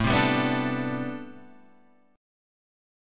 A Minor 11th
Am11 chord {5 3 5 4 3 5} chord